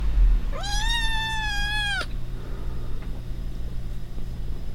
NO.107　子ねこの鳴き声の大きさと周波数成分
子ねこ（２か月）
子ねこより、0.5ｍ離れ地点にて測定
54〜56dB（ねこ側0.5ｍ）、周波数成分800〜8ｋHz
鳴き声ファイル：mp3ファイル（約57KＢ：A特性）
子ねこが何かを要求していると思われる時の騒音レベルは、0.5ｍ離れて54〜56ｄBであった。
また、その鳴き声はやかましく聞こえ800〜8ｋHzの中高音成分であった。